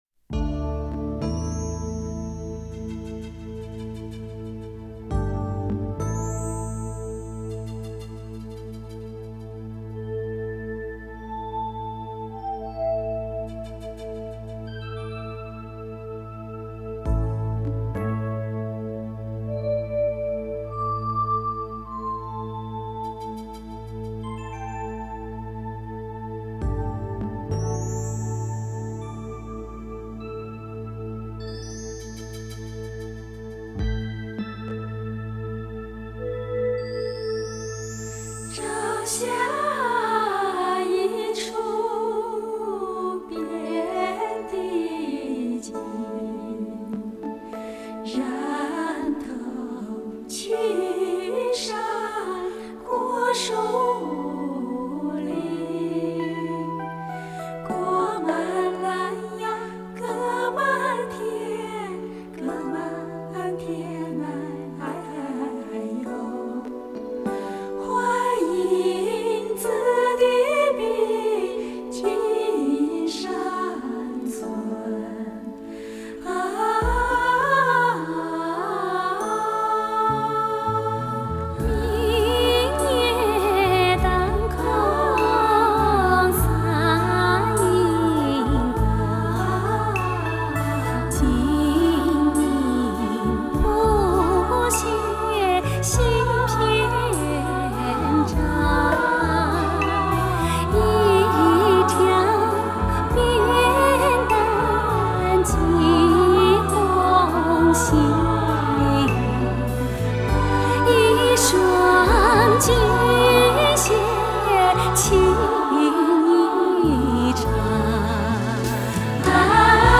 高低频更出色的发烧天碟！